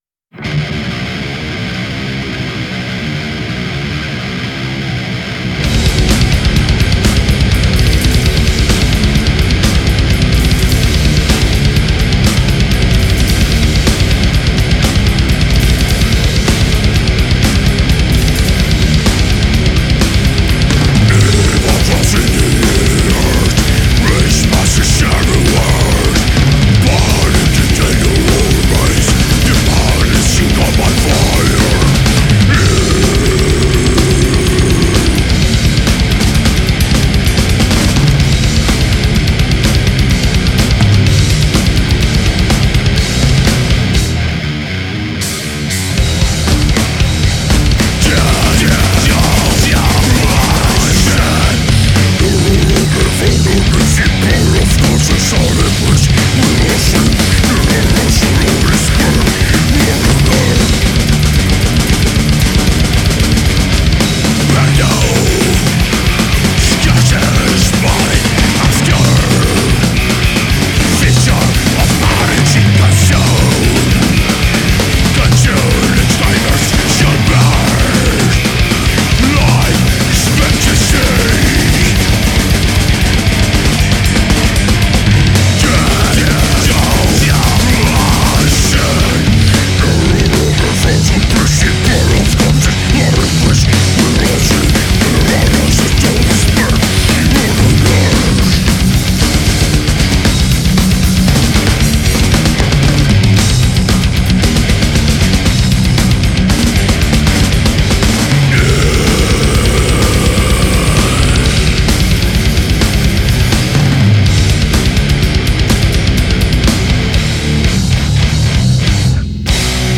Genre: deathmetal.